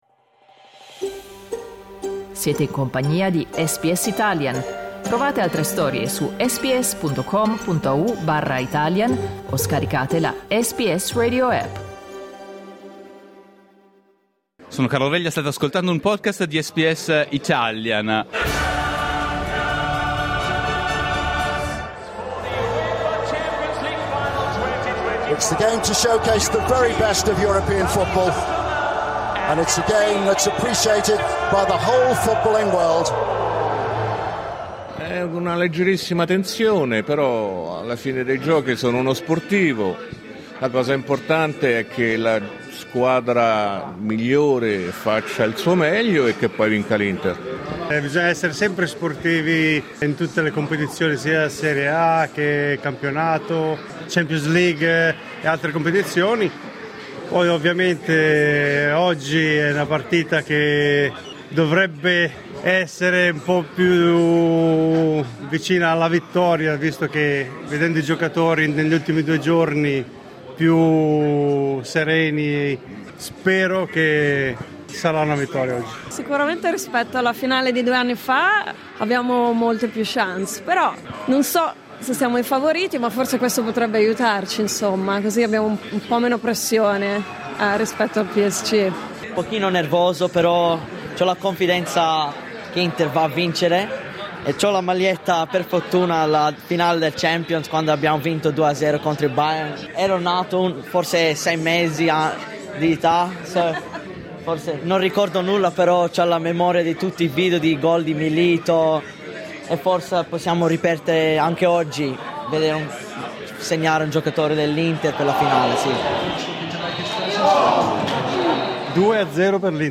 Circa 200 tifosi interisti si sono recati prima dell'alba da Brunetti a Carlton per vedere la finale di Champions League.
Come hanno vissuto la notte di Champions i tifosi dell'Inter? Clicca sul tasto "play" in alto a sinistra per ascoltare le loro voci Per approfondire Il PSG vince la Champions League con il risultato più netto della storia Ascolta SBS Italian tutti i giorni, dalle 8am alle 10am.